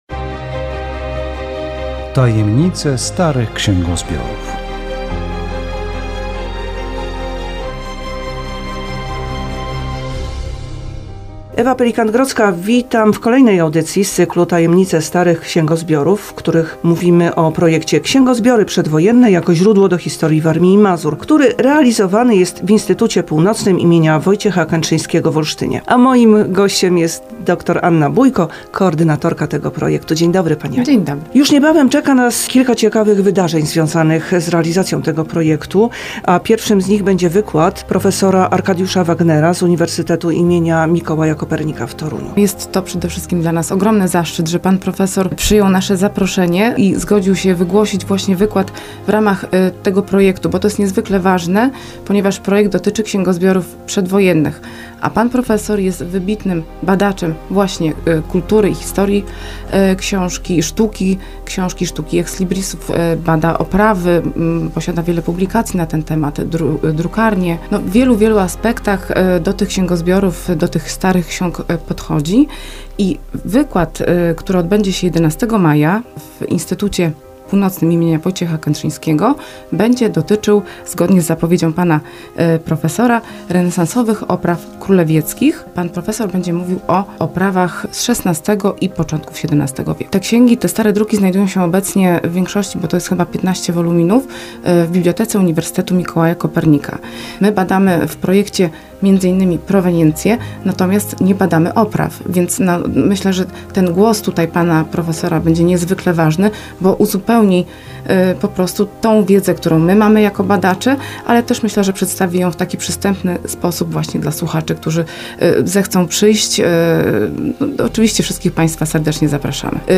Audycja radiowa "Tajemnice starych księgozbiorów".